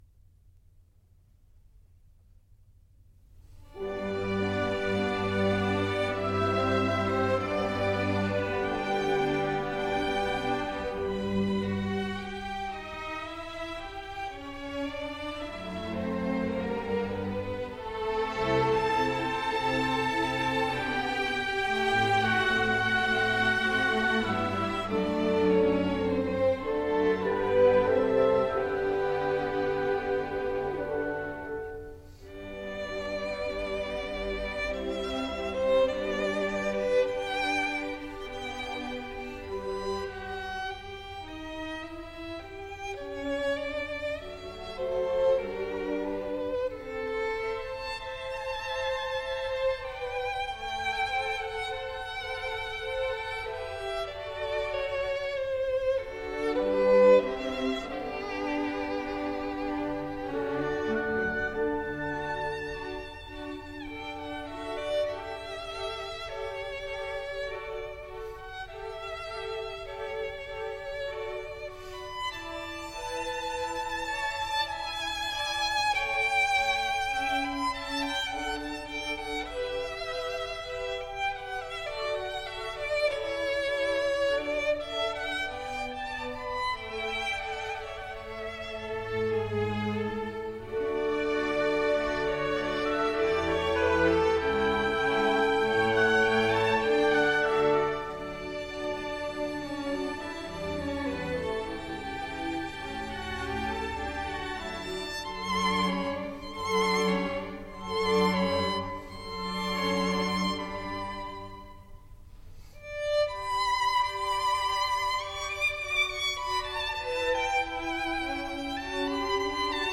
Violin concerto
Violin Concerto in D
Andante